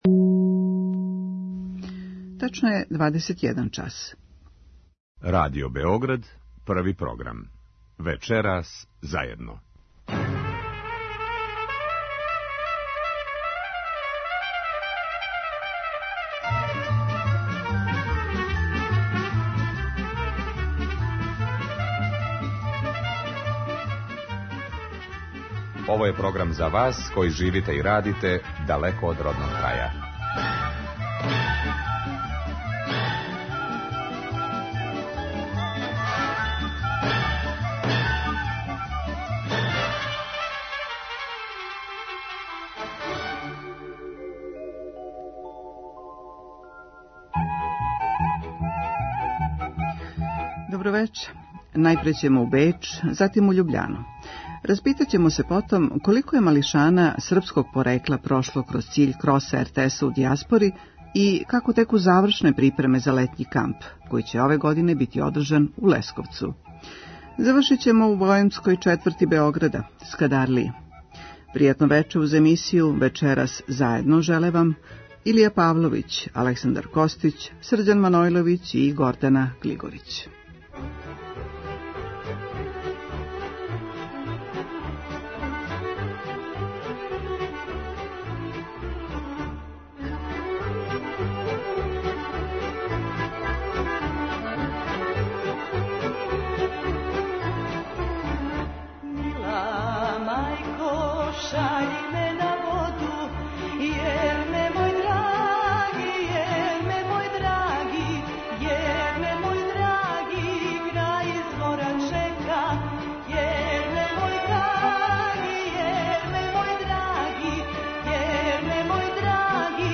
Које су још манифестације планиране у оквиру (првог) Месеца српске културе у Словенији, чућете у укључењу из Љубљане.
Емисија магазинског типа која се емитује сваког петка од 21 час.